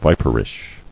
[vi·per·ish]